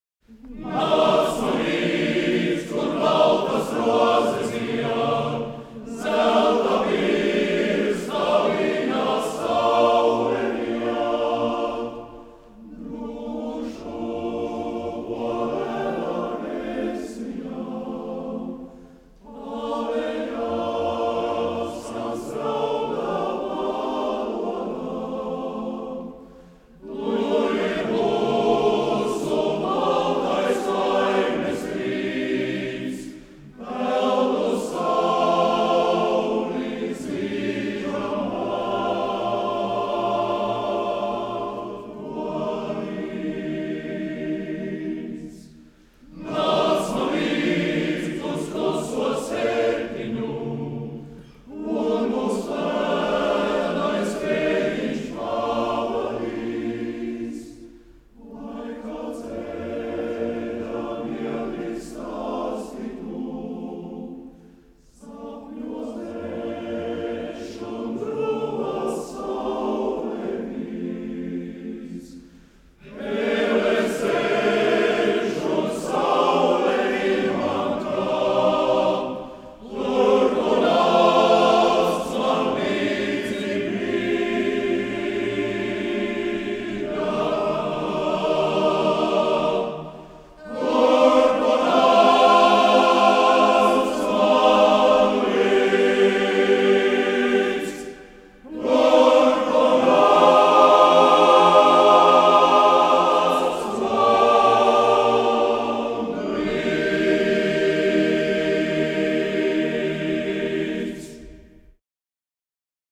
Kora mūzika
Ieraksts no jubilejas koncerta
Lirisks
Latvijas Mūzikas akadēmija